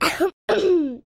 Звуки кашля ребенка
Звук першения в горле у ребенка